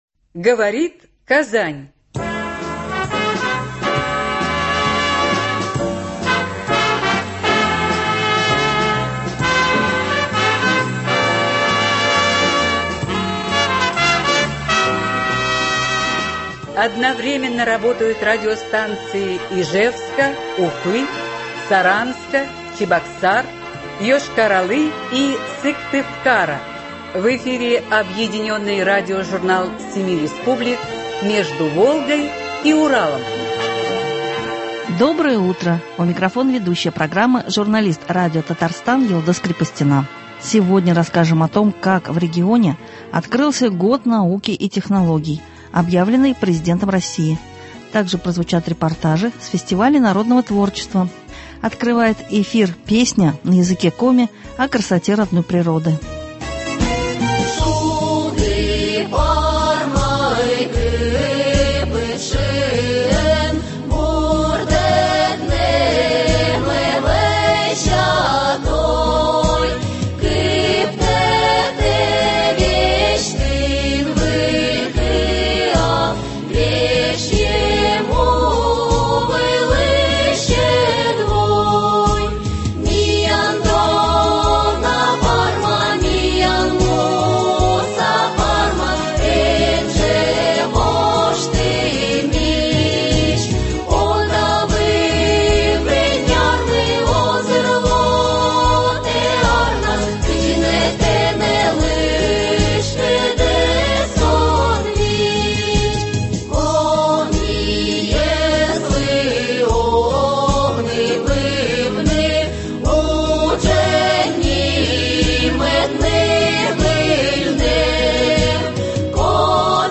Объединенный радиожурнал семи республик.
Сегодня расскажем о том, как в регионе открылся Год науки и технологий, объявленный Президентом России. Также прозвучат репортажи с фестивалей народного творчества.